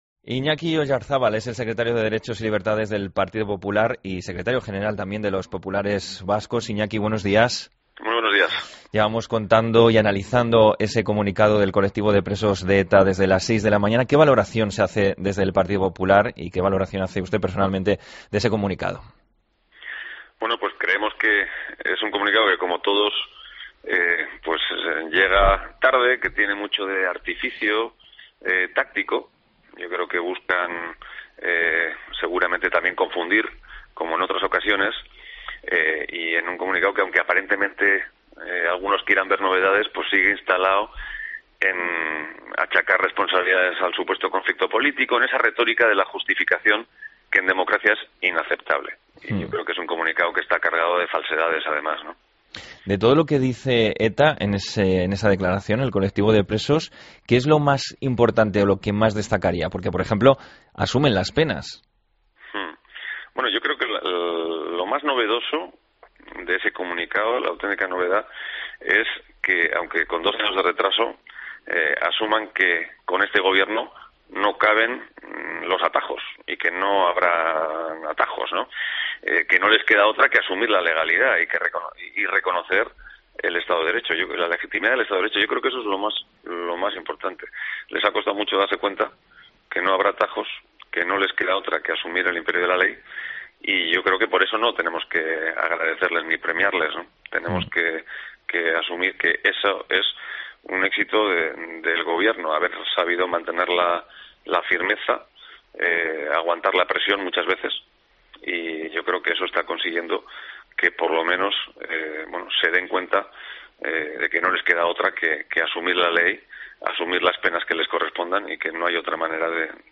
Escucha la entrevista a Iñaki Oyarzábal en La Mañana del Fin de Semana